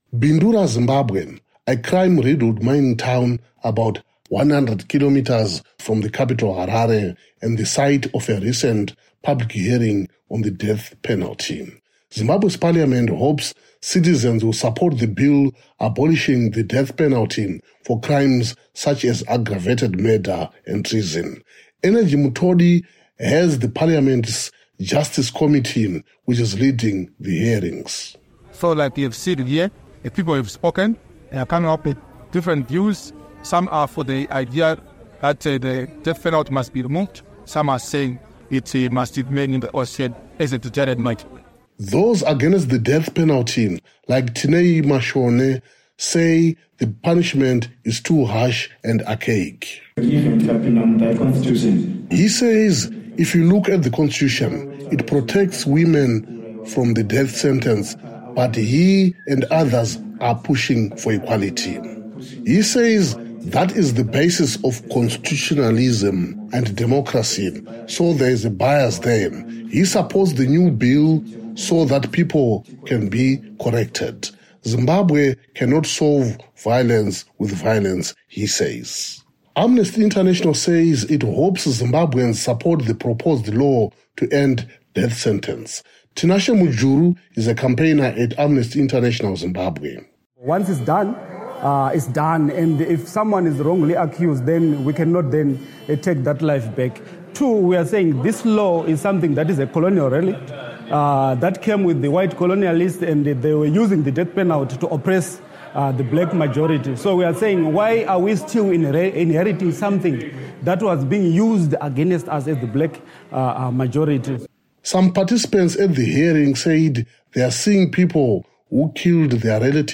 joins the public hearing in Bindura, about 90 kilometers northeast of Harare